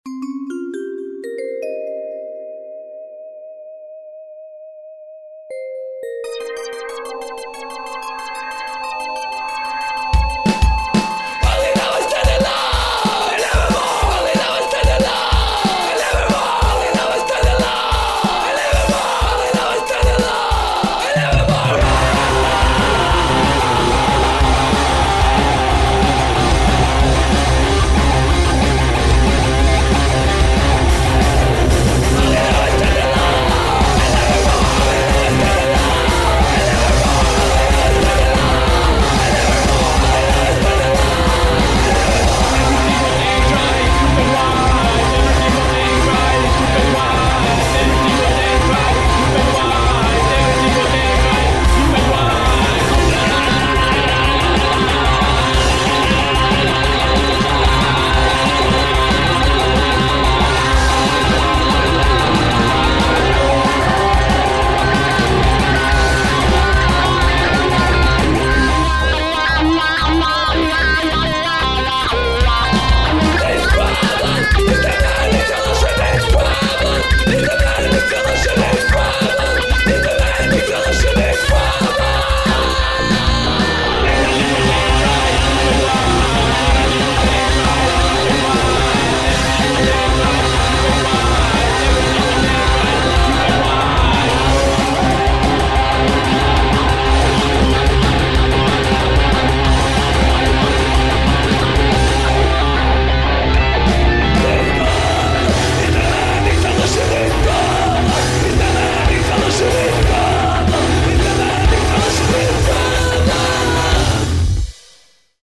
gitara, vokal
bass, vokal
bubnjevi